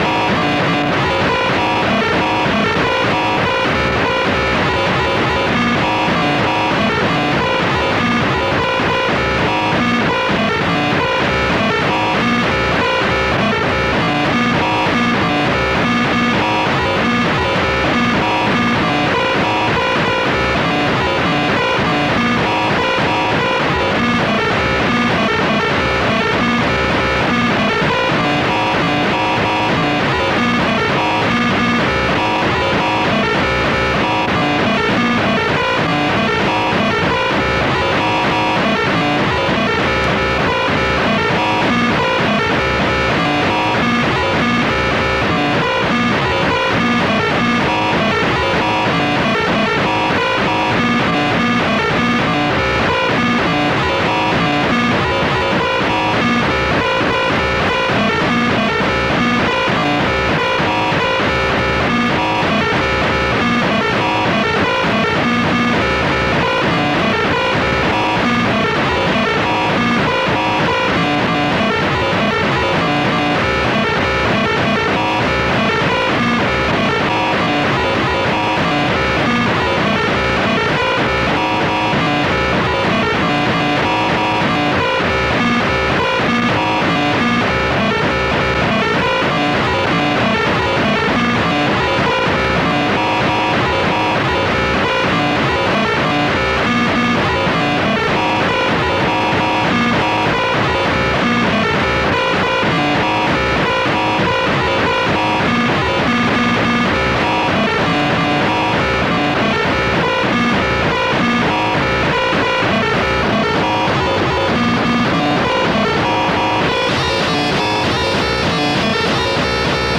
Música bélica.